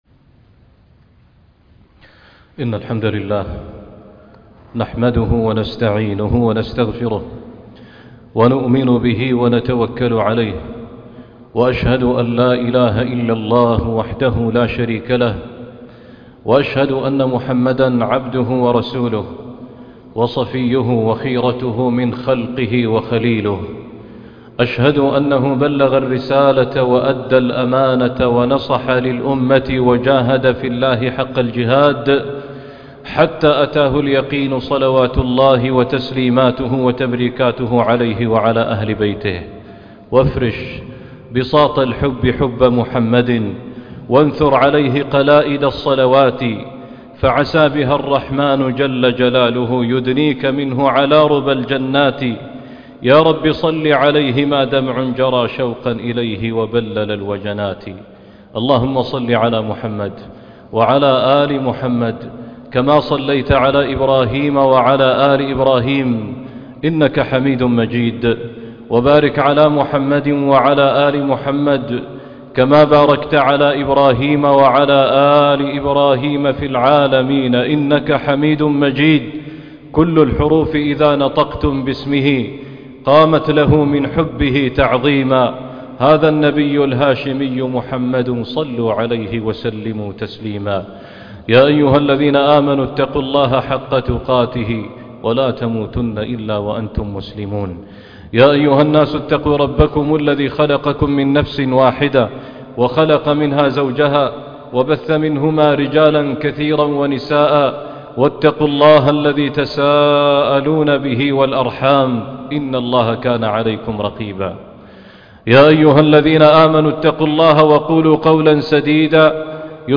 فاتخذه وكيلا - خطبة الجمعة